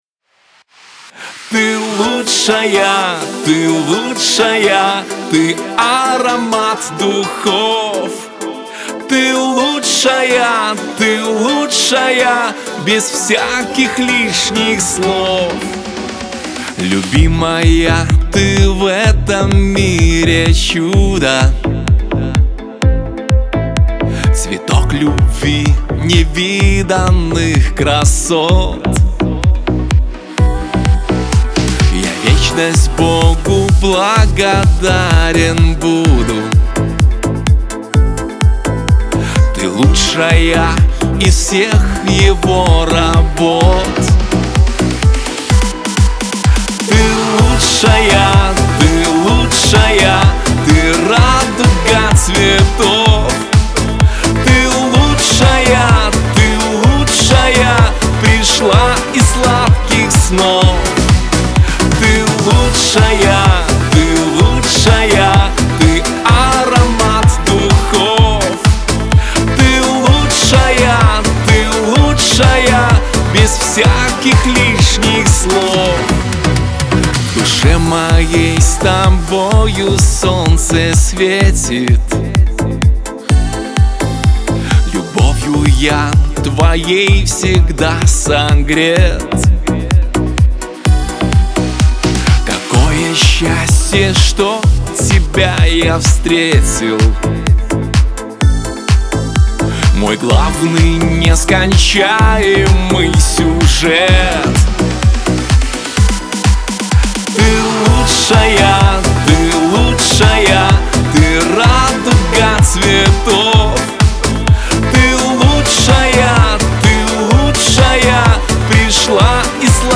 pop , диско
эстрада